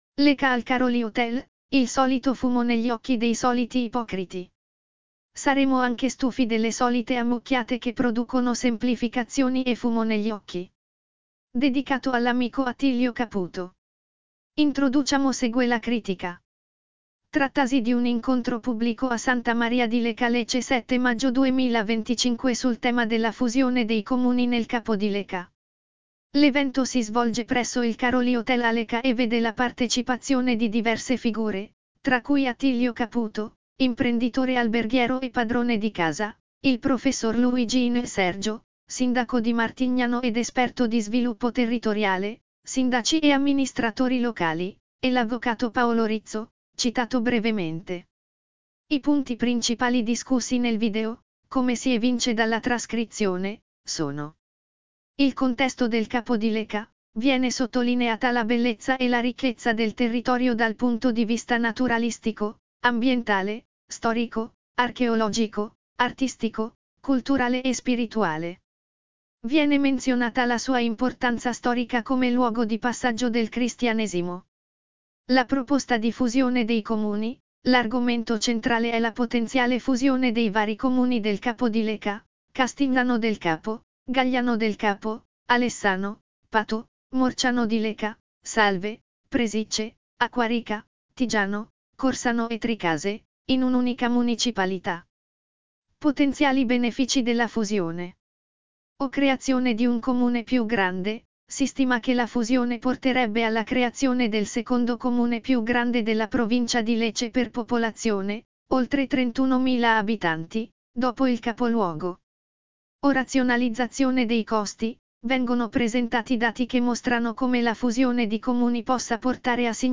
Trattasi di un incontro pubblico a Santa Maria di Leuca (LE) 07/05/2025 sul tema della fusione dei comuni nel Capo di Leuca.